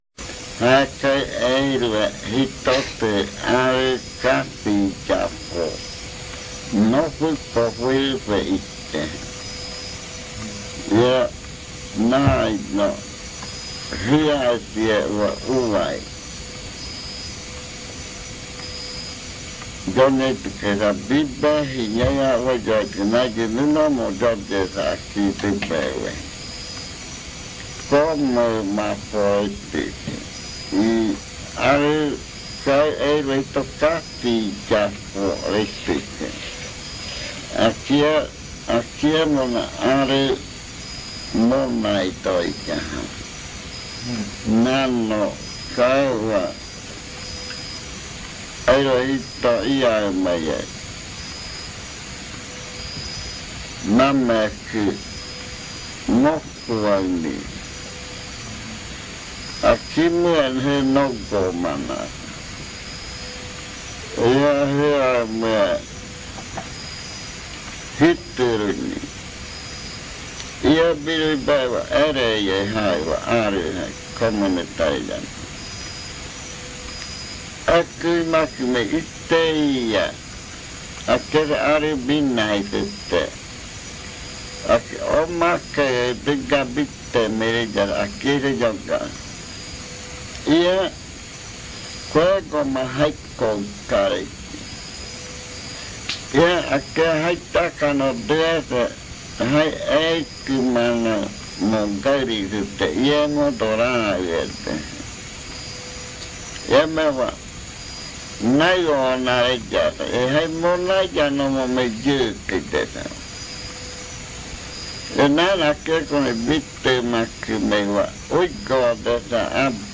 Estas dos narraciones (Murui, Muina-Murui arɨ biyakɨno y Moniyamena arɨ biyakɨno) fueron traducidas en Leticia, palabra por palabra.